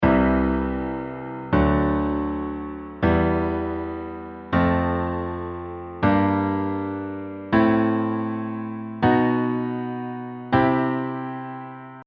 Here’s an example where I play major on the 2nd step instead of minor, minor on the 5th step instead of major, and major on the 7th step instead of diminished.
It’s hard to pinpoint exactly what sounds strange, but something does sound off.